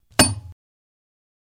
Hit Metal Pipe On Cement